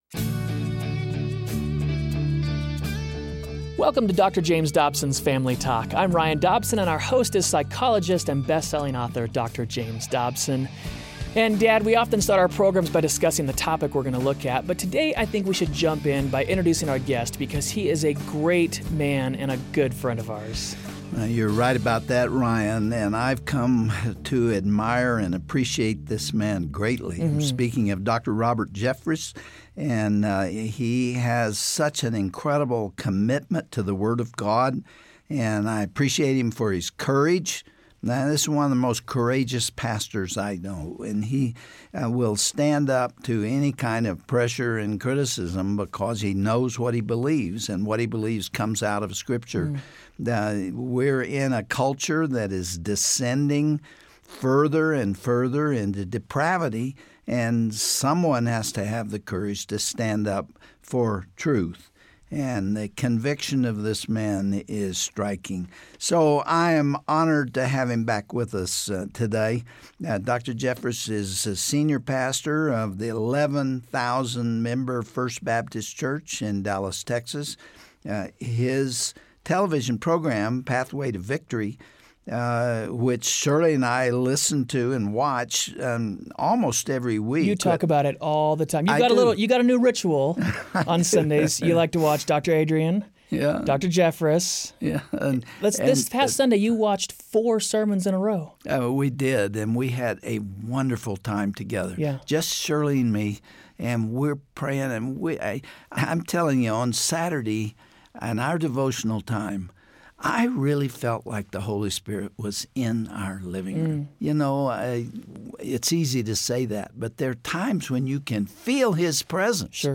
So what are the most important questions a person can ask? On the next edition of Family Talk, Dr. James Dobson will interview Dr. Robert Jeffress about lifes most important questions.